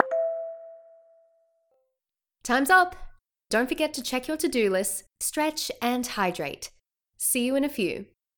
break_alert_1.wav